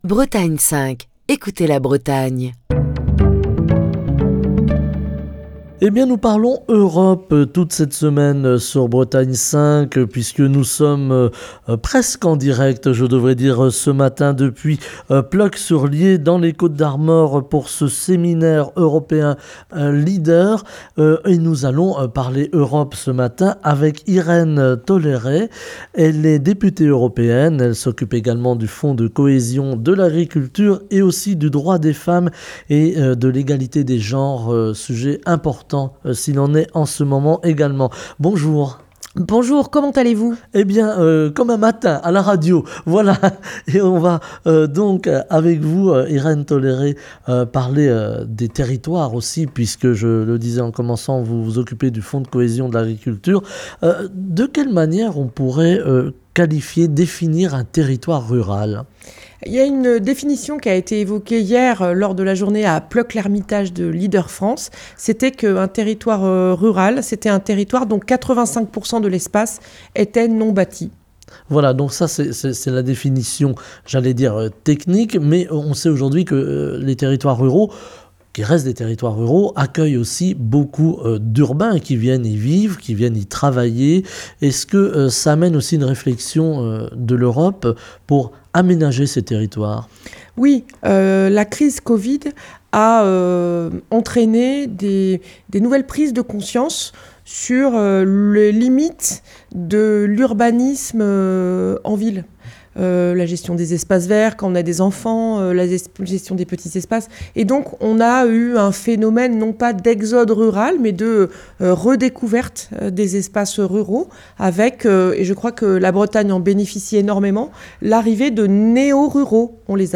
Semaine Europe et ruralité - Bretagne 5 est en direct de Plœuc-L'Hermitage pour le séminaire LEADER.